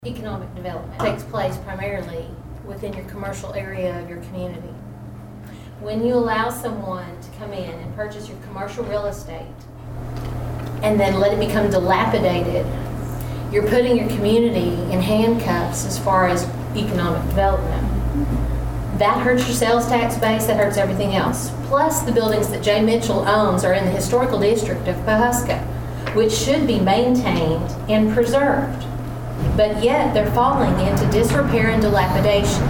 At a Tuesday evening city council meeting